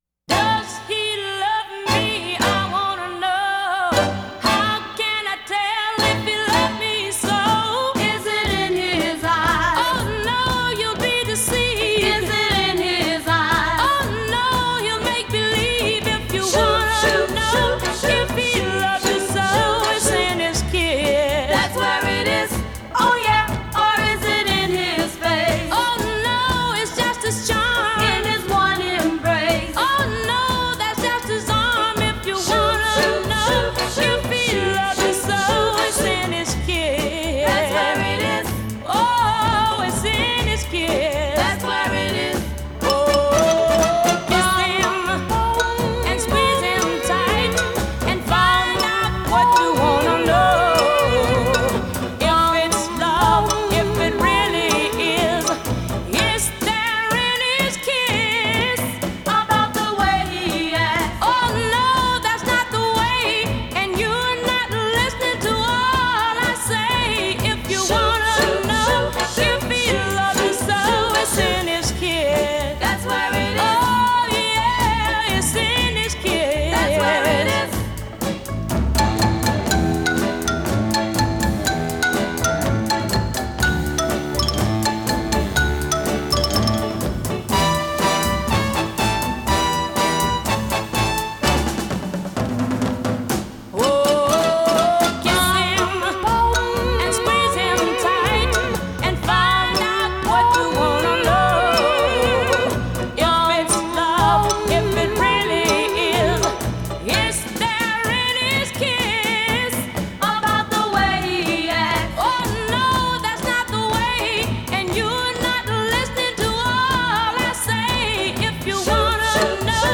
This is the stereo version.